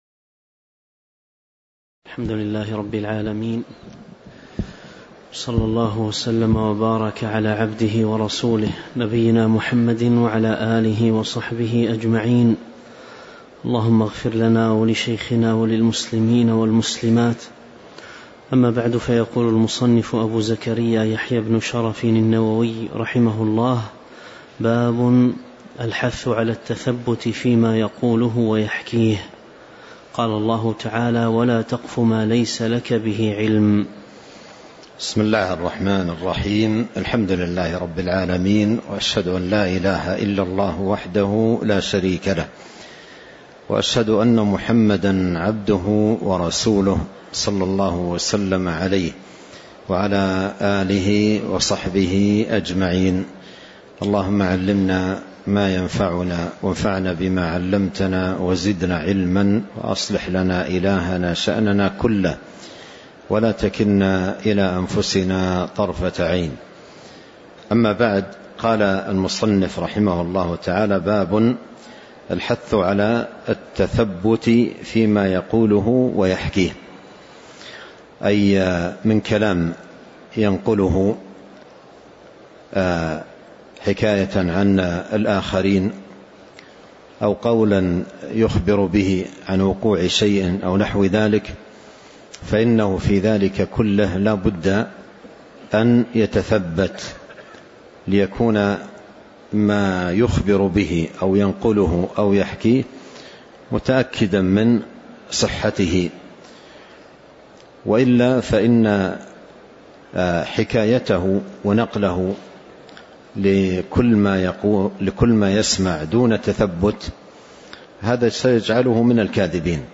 الدروس العلمية بالمسجد الحرام والمسجد النبوي